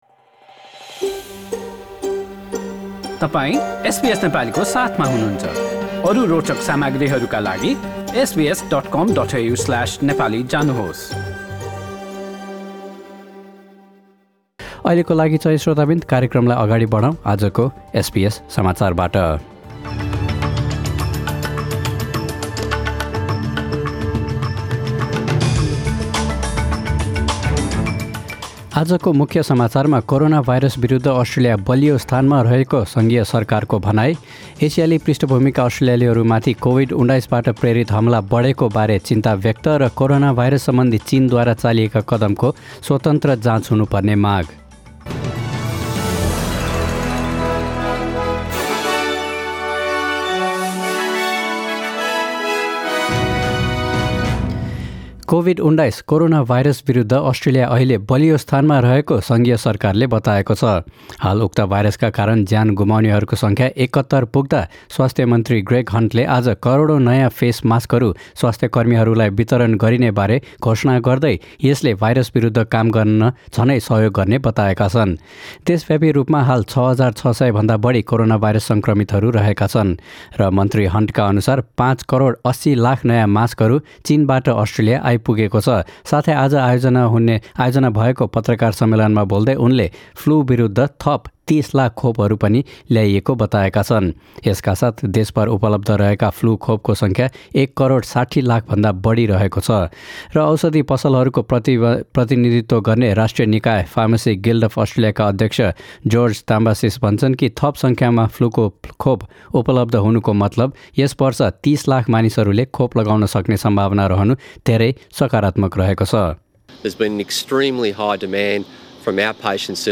Australian Health Minister Greg Hunt speaks to the media during a press conference. Source: AAP